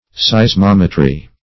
Search Result for " seismometry" : The Collaborative International Dictionary of English v.0.48: Seismometry \Seis*mom"e*try\, n. The mensuration of such phenomena of earthquakes as can be expressed in numbers, or by their relation to the coordinates of space.